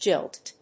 /dʒílt(米国英語)/